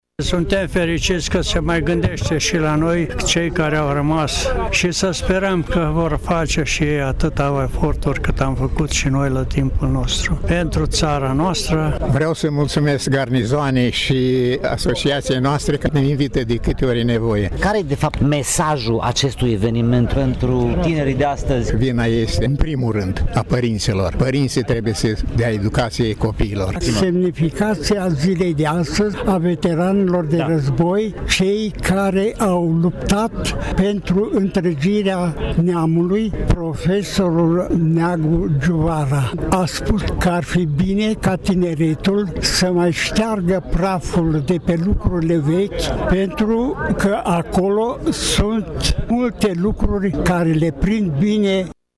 Ca în fiecare an cu ocazia Zilei Veteranilor de Război, instituțiile publice locale și Garnizoana Tîrgu-Mureș au organizat, în Parcul Eroilor, o ceremonie militară de depuneri de coroane.